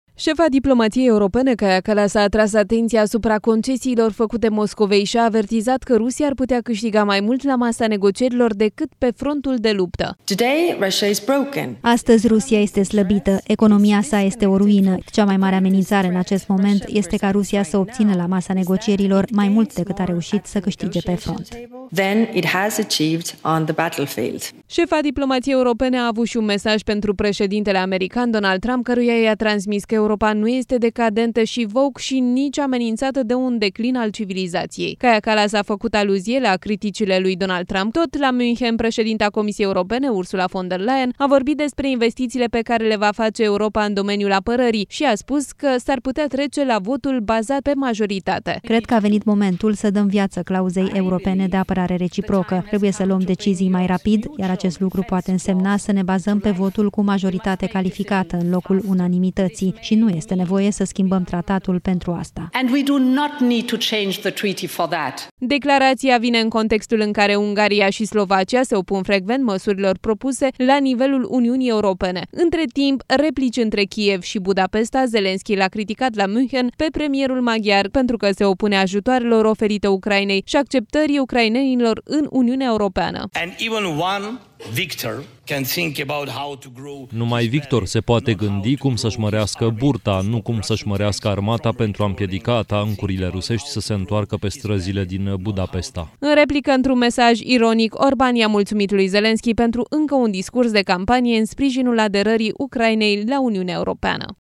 În ultima zi a Conferinței de Securitate de la Munchen, duminică, șefa diplomației europene, Kaja Kallas, a pledat pentru o poziție mai fermă față de Rusia la negocierile privind încheierea războiului din Ucraina.
16feb-07-July-SNDW-declaratii-Munchen-rafuiala-lui-Orban.mp3